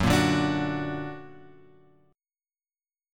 Fsharp-Augmented-Fsharp-2,1,0,x,3,2.m4a